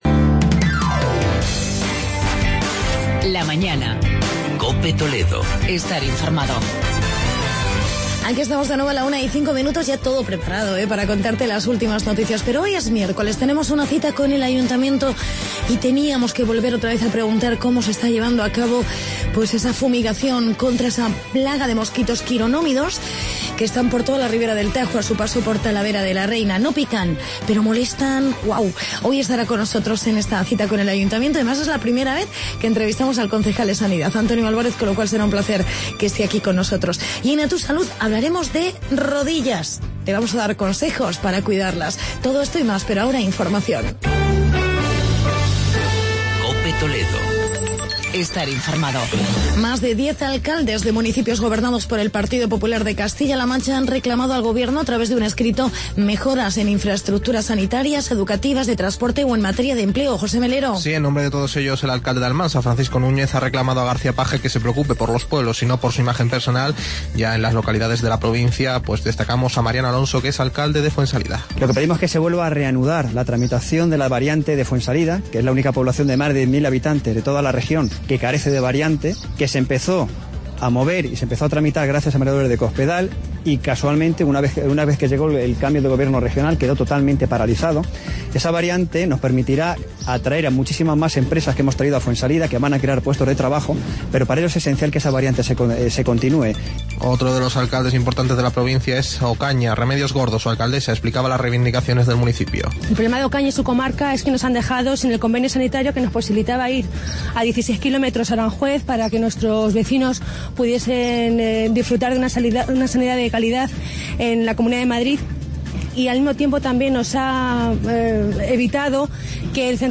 Hablamos con el concejal Antonio Alvarez sobre la plaga de mosquitos en Talavera de la Reina
entrevista